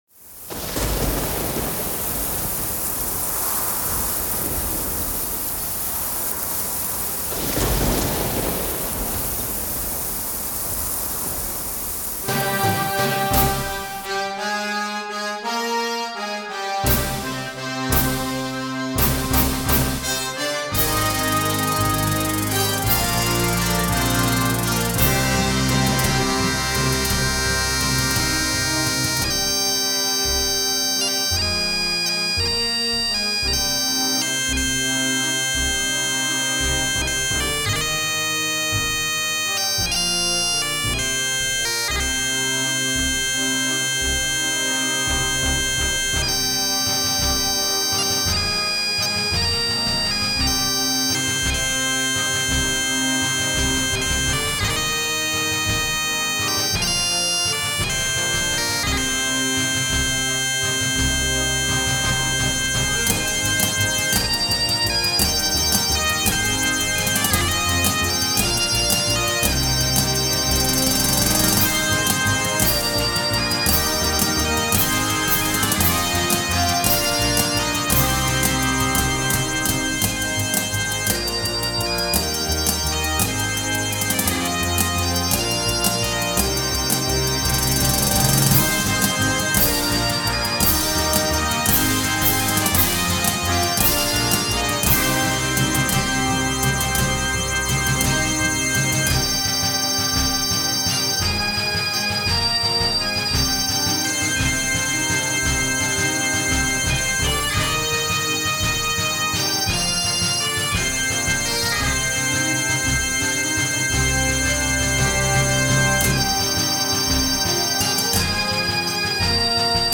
Timpani
Tubular Bells